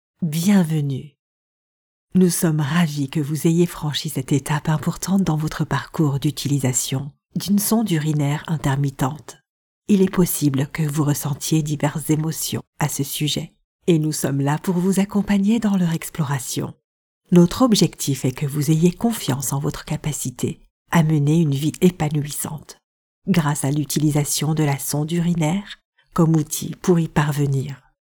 My voice is natural, friendly, fun and trustworthy. I have a genuine tone and my range is from fun & bubbly to serious and honest.